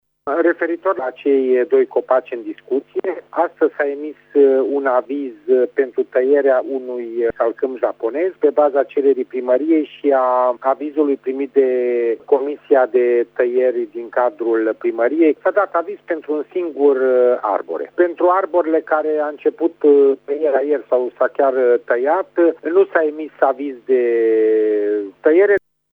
Șeful Agenției de Protecție a Mediului Mureș, Dănuț Ștefănescu, a explicat că instituția a acordat astăzi o autorizație de tăiere pentru alt copac.